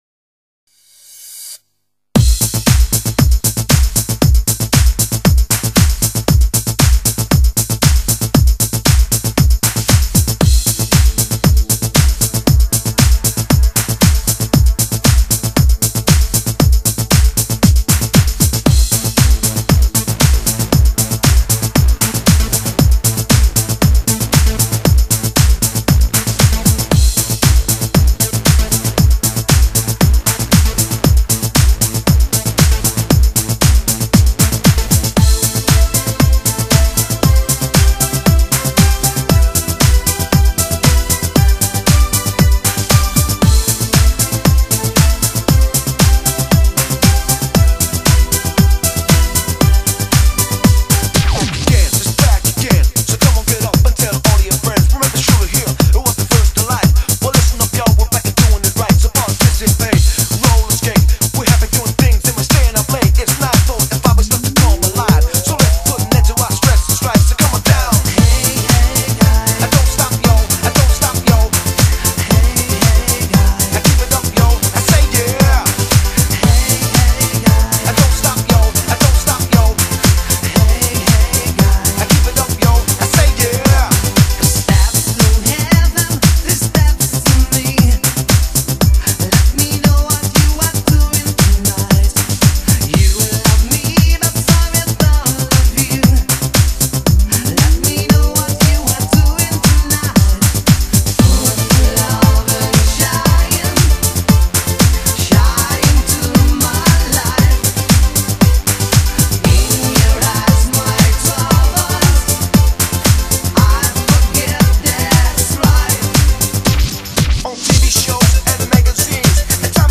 欧舞嘉年华